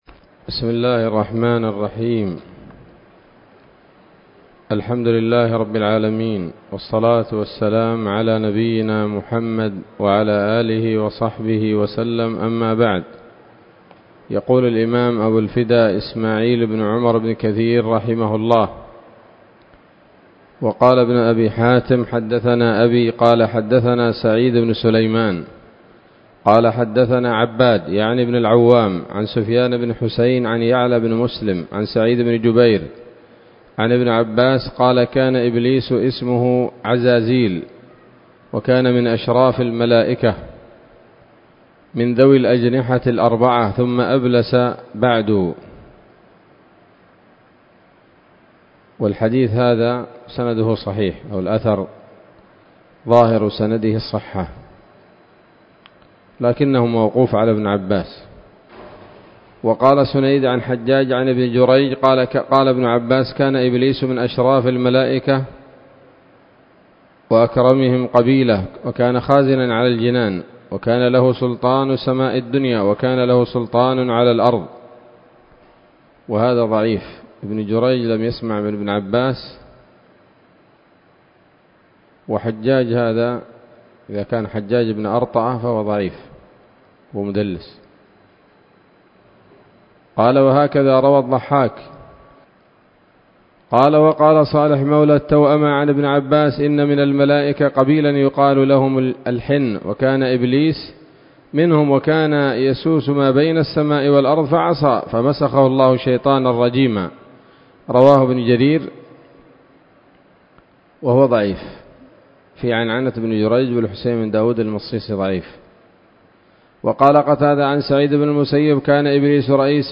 الدرس التاسع والثلاثون من سورة البقرة من تفسير ابن كثير رحمه الله تعالى